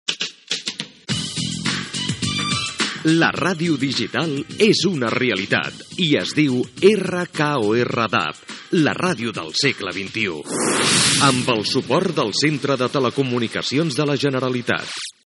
Indicatiu de l'emissora emetent a la banda digital de radiodifusió DAB
Fragment sonor extret del DVD "Guía de la radio" 2008